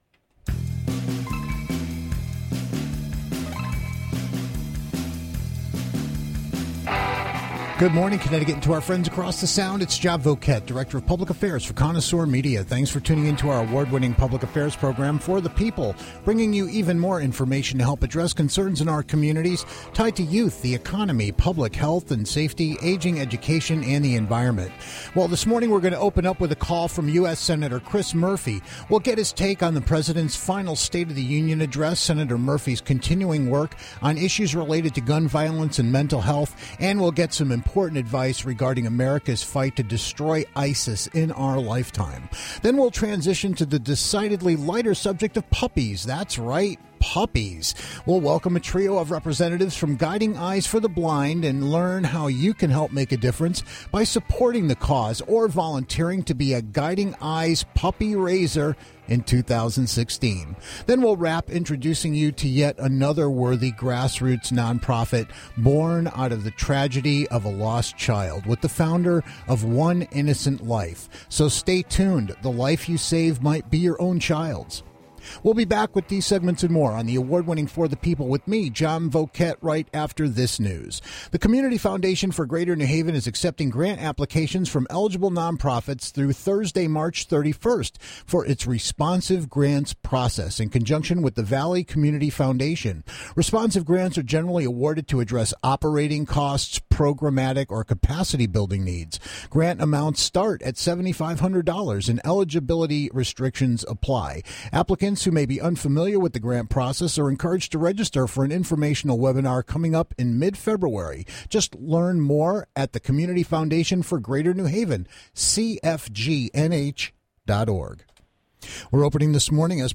Then we'll transition to the decidedly lighter subject of puppies - that's right - puppies, with a trio of representatives from Guiding Eyes for the Blind.